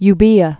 (y-bēə) also Ev·voia (ĕvyä)